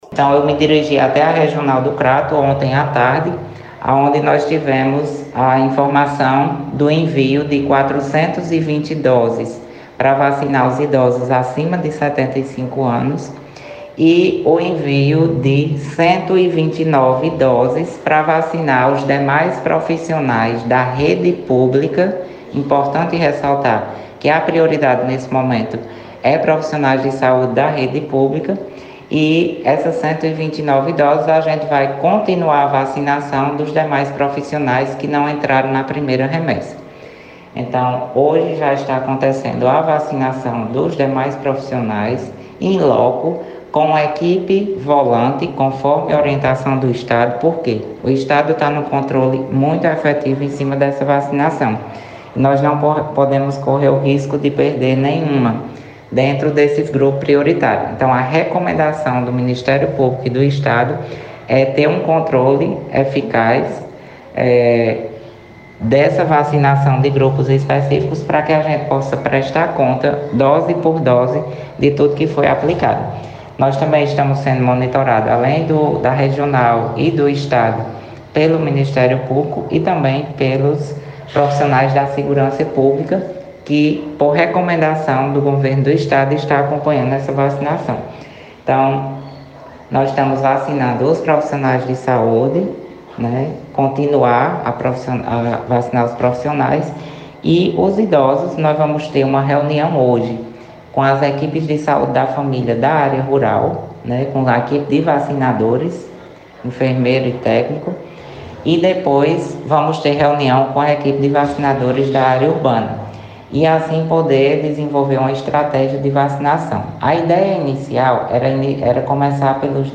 As informações são do secretário de Saúde Ivo Leal, à FM Cultura, na tarde desta quarta-feira, 27, que disse também que a imunização já começou.
Secretario-de-Saude-Ivo-Leal.mp3